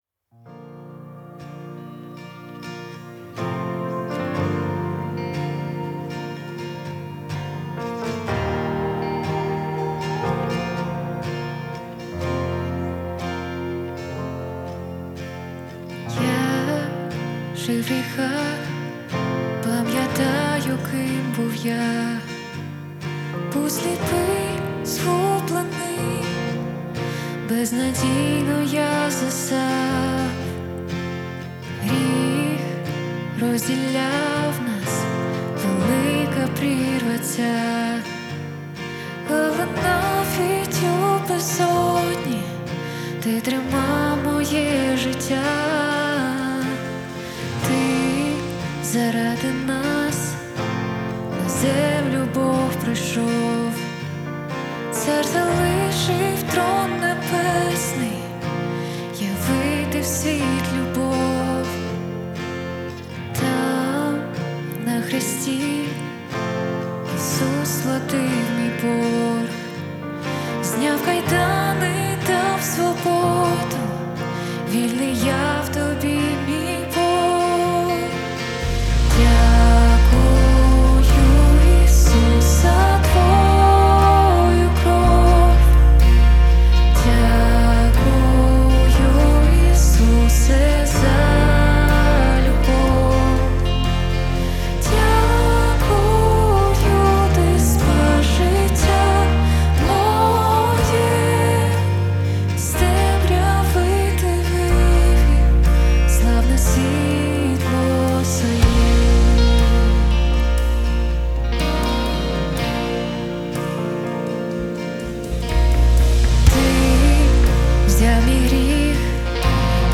174 просмотра 25 прослушиваний 2 скачивания BPM: 61